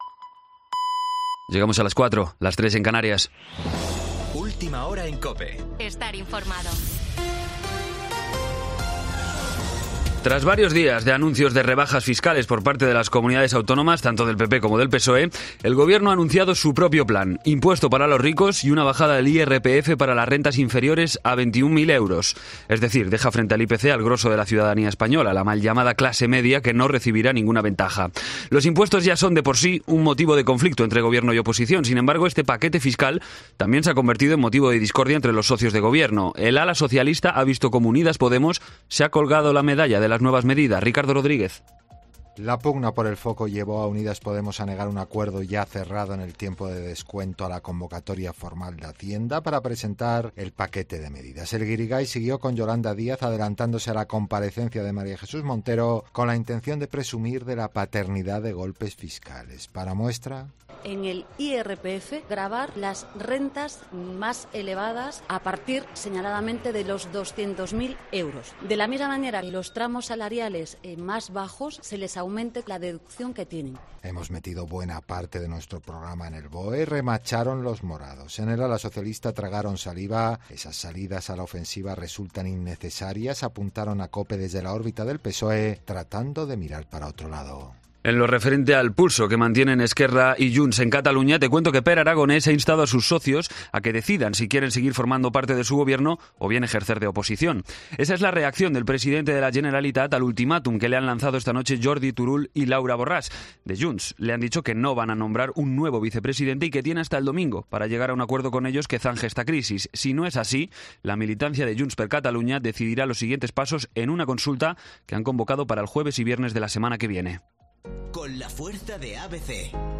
Boletín de noticias COPE del 30 de septiembre a las 04:00 hora